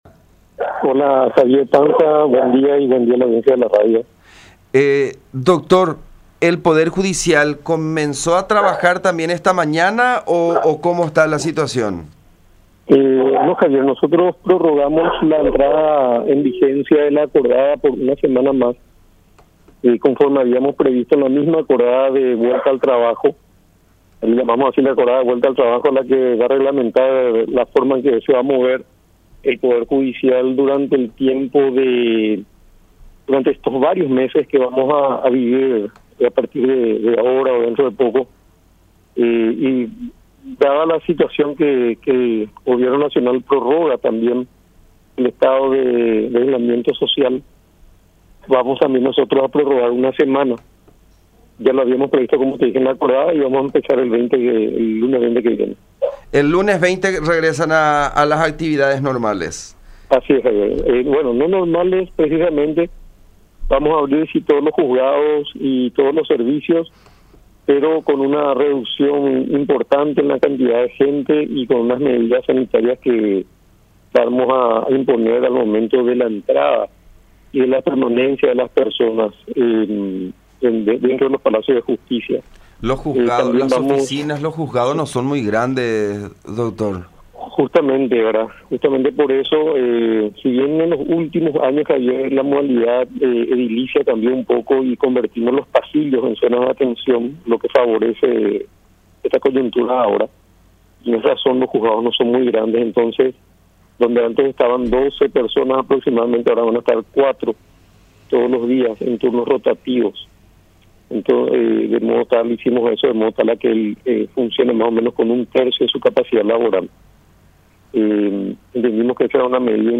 “Mediante la acordada que firmamos, regresaremos el lunes 20 de abril, siempre con el cumplimiento de las medidas sanitarias”, dijo Alberto Martínez Simón, presidente de la Corte Suprema de Justicia (CSJ), en contacto con La Unión.